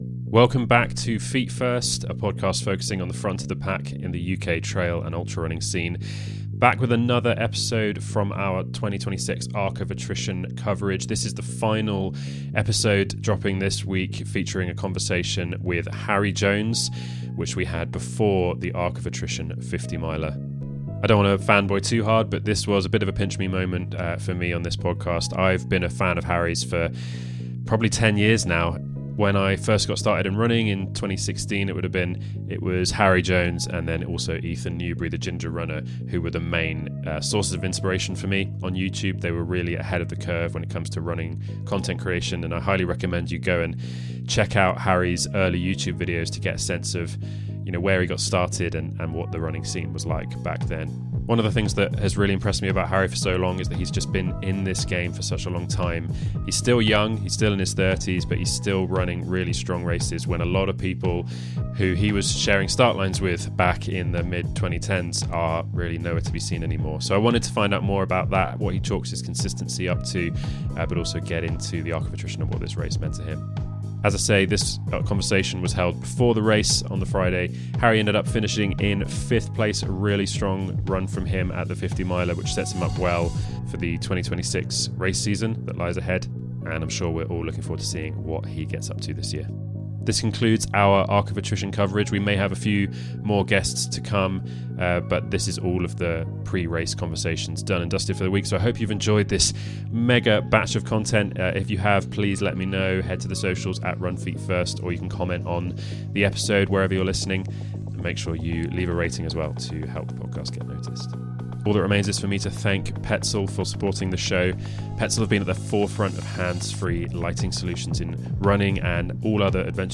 A pre-race chat